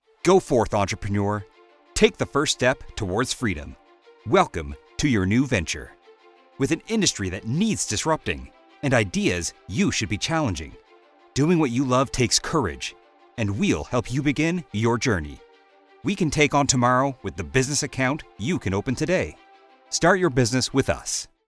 Male
I provide a warm, deep range with various styles.
Radio Commercials
Words that describe my voice are Deep, Warm, Narrative.
All our voice actors have professional broadcast quality recording studios.